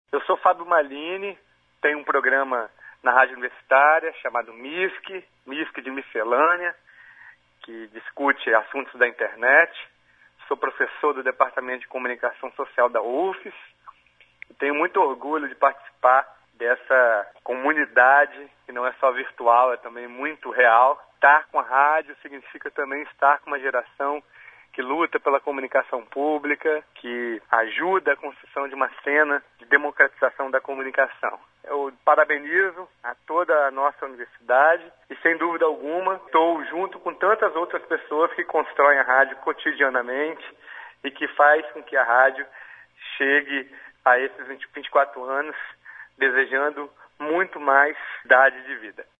Confira os depoimentos: